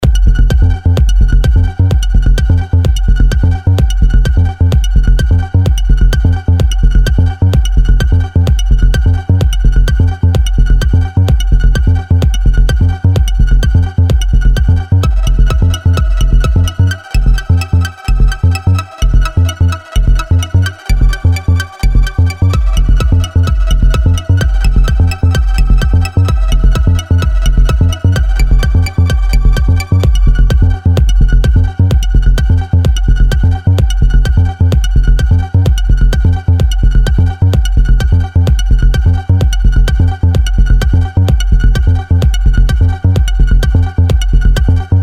Стиль: Techno